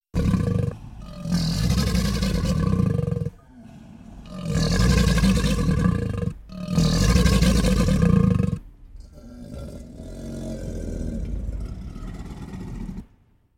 crocodile-sound